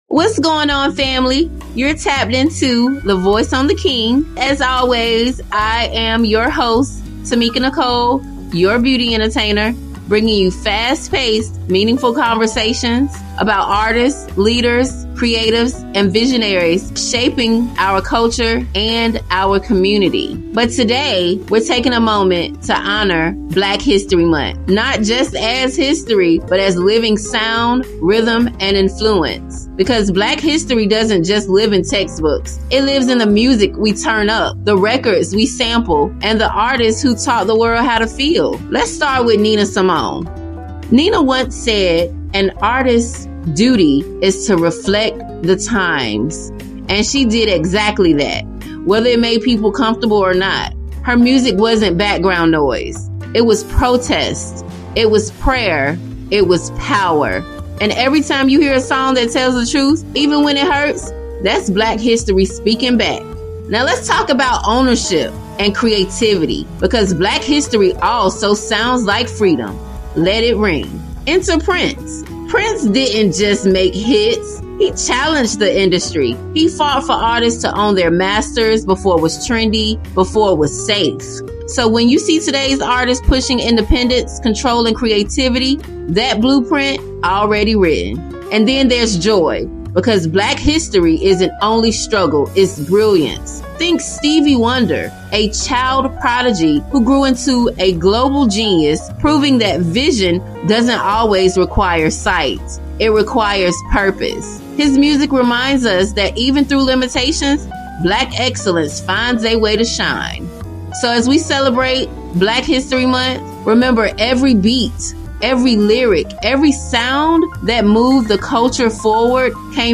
The Voice on The King a quick conversation where local and national leaders, business owners and brands, artists and authors share their voices and stories with the world!